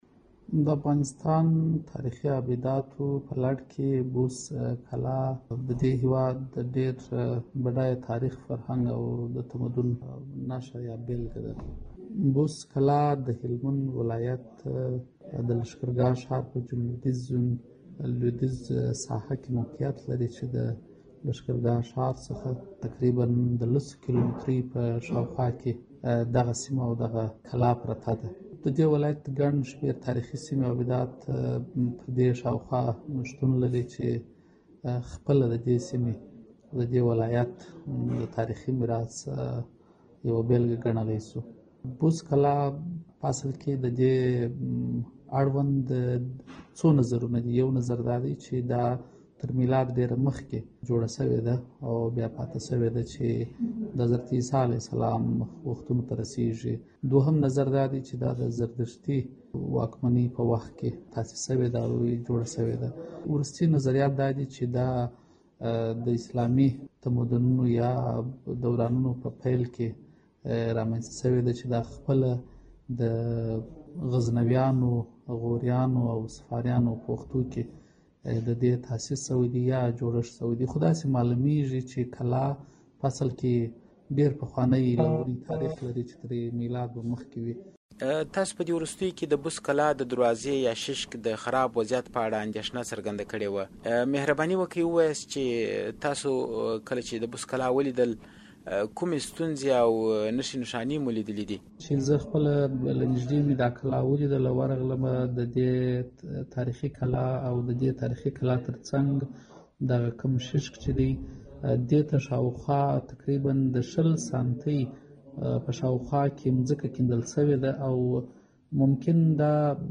راډیويي مرکې